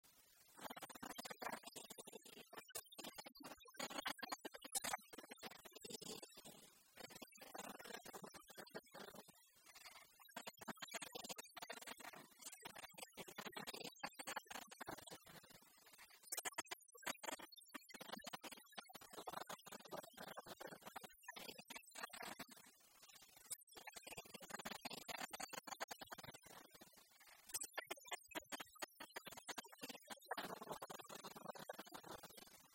Genre strophique
Enquête Arexcpo en Vendée-C.C. Yonnais
Pièce musicale inédite